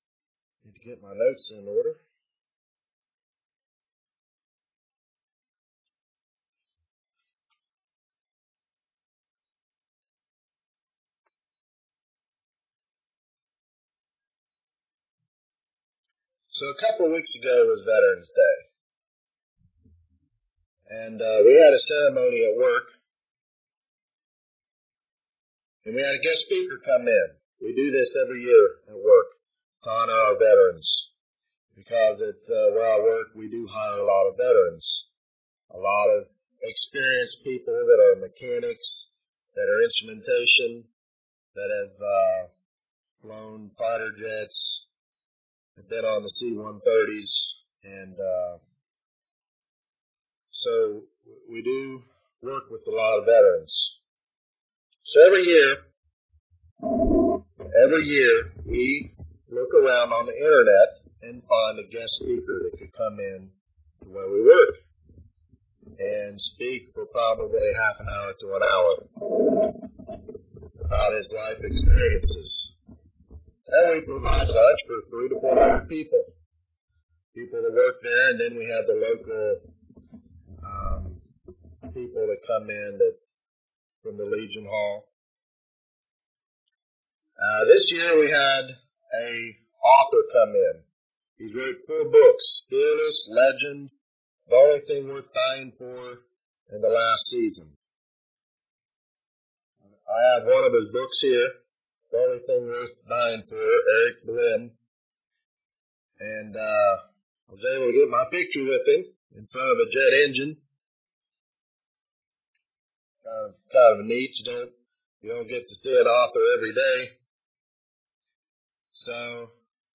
This sermon addresses a very important topic.